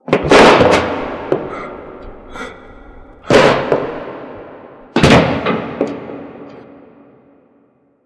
scream_9.wav